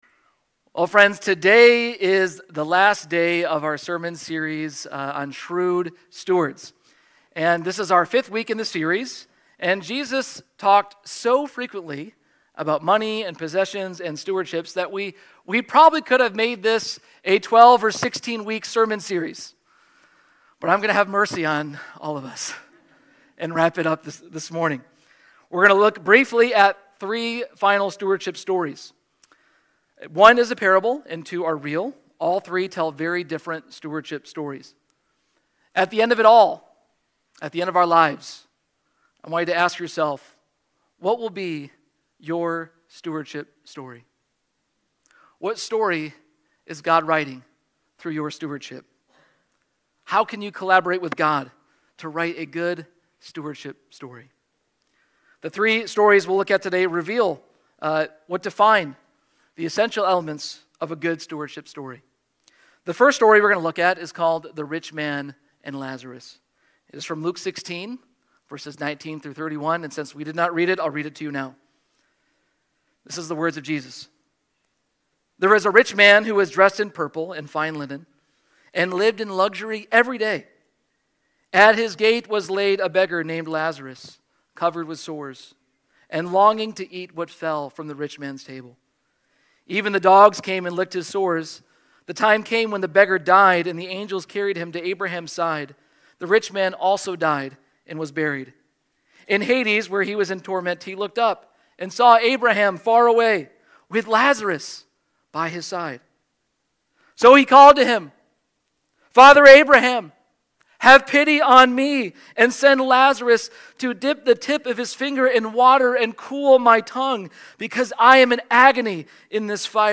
Sermons | Faith Covenant Church